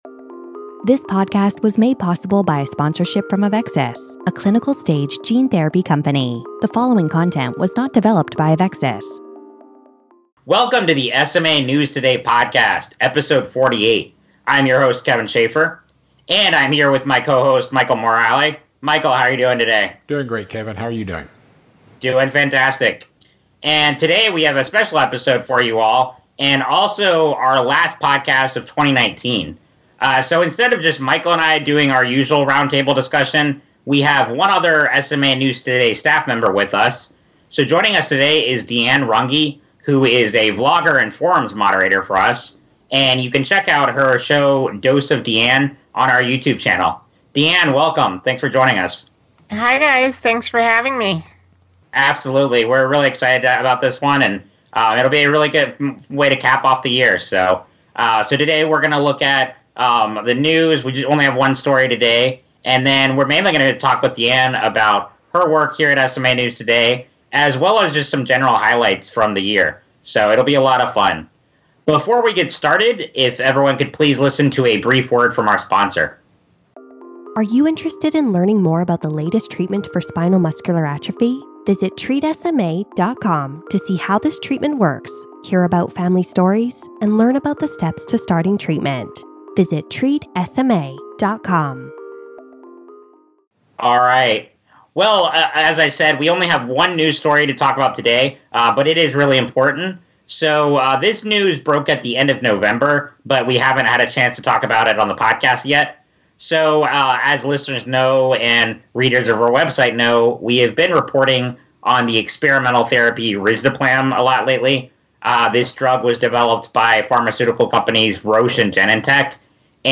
#48: Roundtable Discussion December 2019